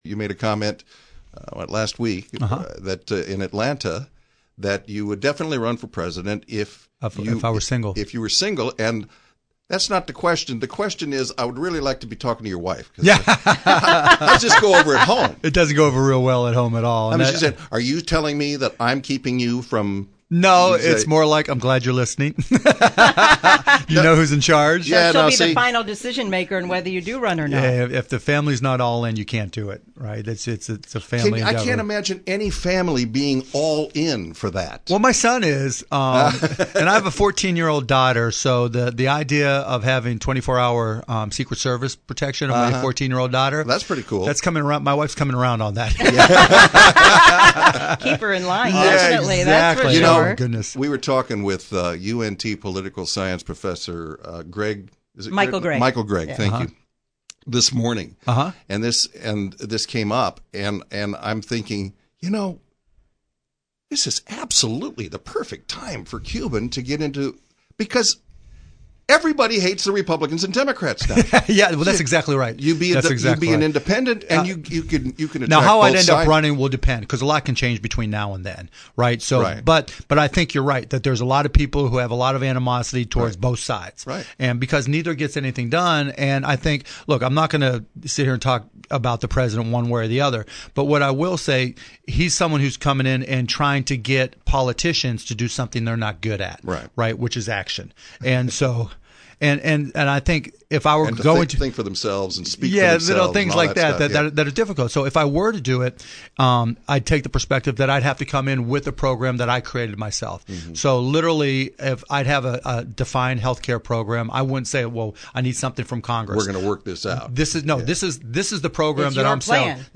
DALLAS (WBAP/KLIF News) — Dallas Mavericks’ owner Mark Cuban joined WBAP and KLIF in studio Monday morning to discuss a variety of topics, including the job President Trump is doing, whether or not he will run for President in 2020, the national anthem kneeling controversy, and of course, the 2017-2018 Mavericks season.